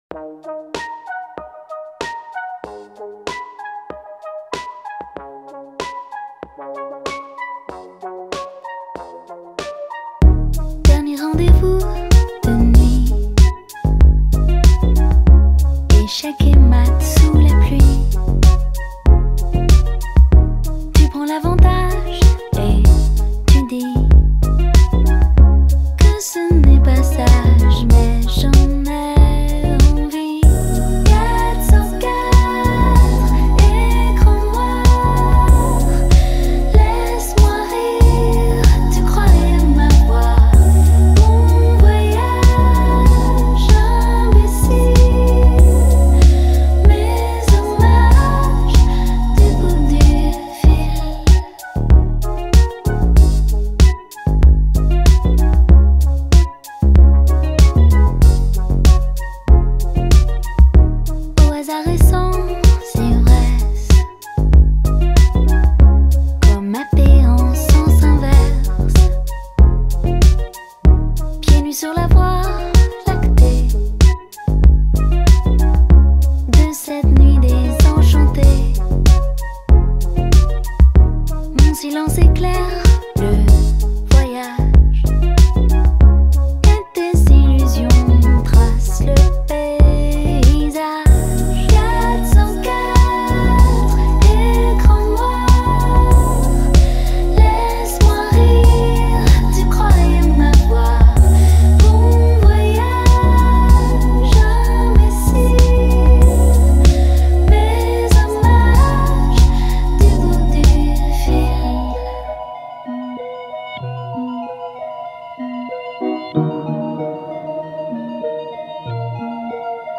# alternative/indé